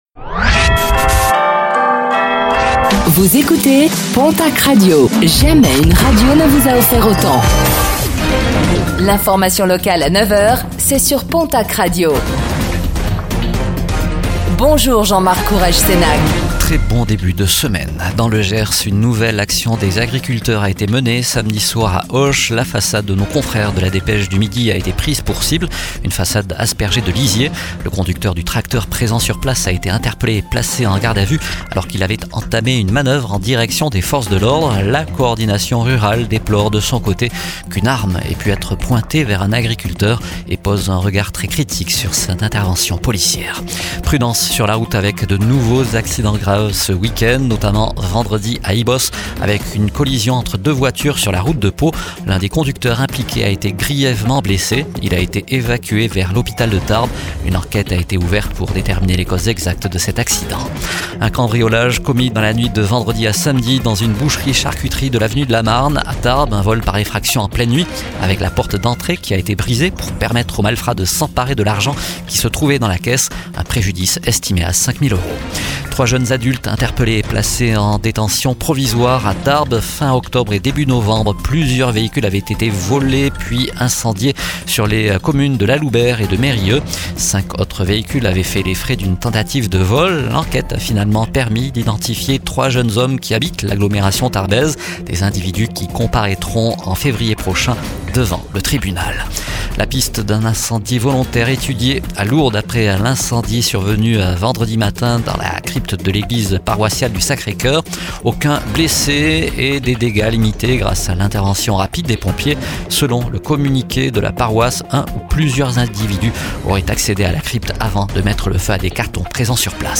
09:05 Écouter le podcast Télécharger le podcast Réécoutez le flash d'information locale de ce lundi 29 décembre 2025